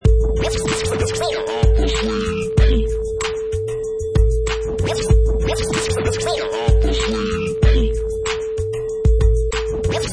Sound files: Vinyl Crazy Scratch 105 bpm with beat 3
Professional killer vinyl scratch on top of hip hop beat, perfect for sampling, mixing, music production, timed to 105 beats per minute
Product Info: 48k 24bit Stereo
Category: Musical Instruments / Turntables
Try preview above (pink tone added for copyright).
Vinyl_Crazy_Scratch_105_bpm_with_beat_3.mp3